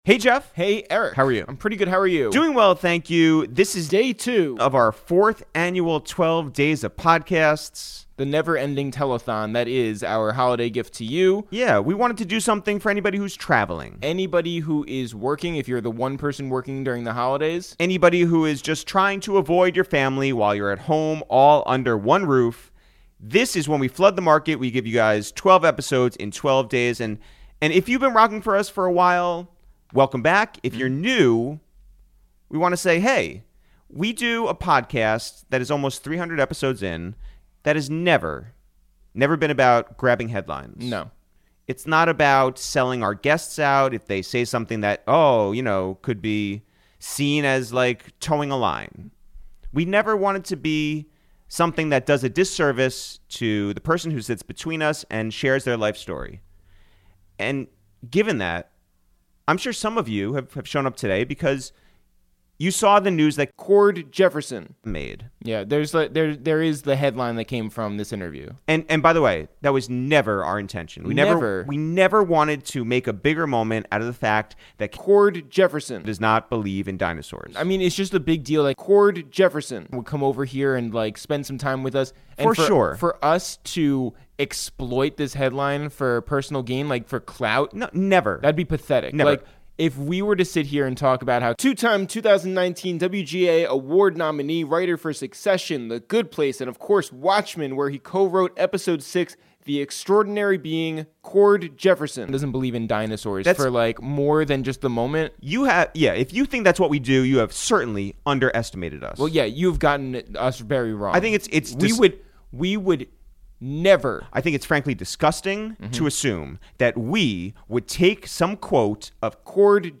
Today on A Waste of Time with ItsTheReal, for Day 2 of the #12DaysOfPodcasts, we went to Los Angeles to sit down with two-time 2019 WGA Award nominee, writer for Succession, The Good Place and of course Watchmen, where he co-wrote Episode 6, This Extraordinary Being, Cord Jefferson for a wide-ranging and personal conversation!